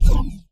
magic_cast_generic03.wav